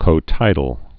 (kō-tīdl)